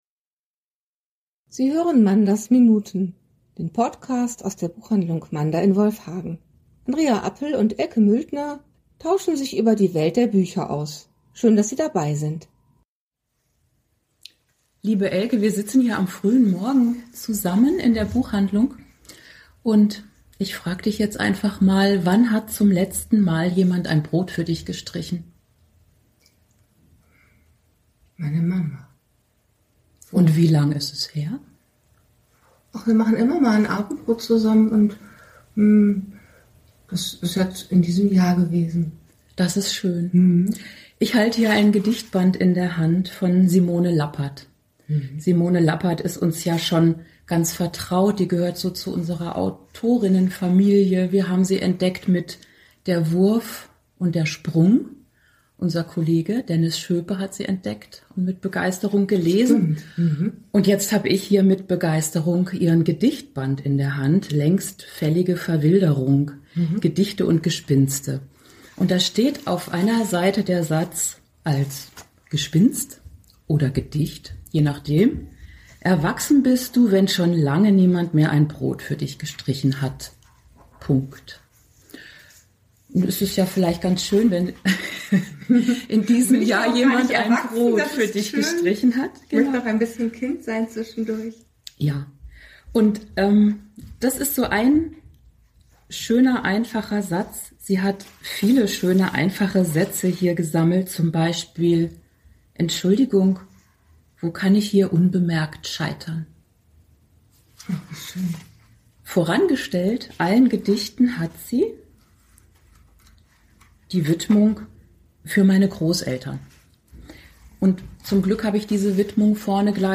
im Gespräch über Bücher ~ Manders Minuten Podcast